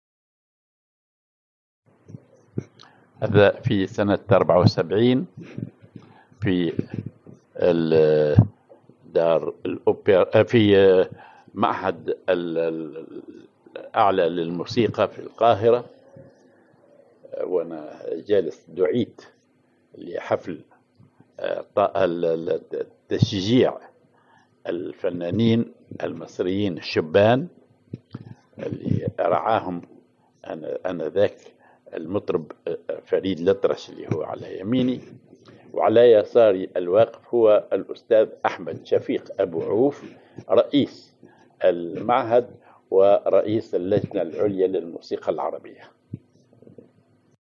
حفل لتشجيع الفنانين المطربين الشبان الذي يرعاهم المطرب فريد الأطرش في المعهد الأعلى للموسيقى في القاهرة
en A ceremony to encourage young singers sponsored by the singer Farid El Atrache at the Higher Institute of Music in Cairo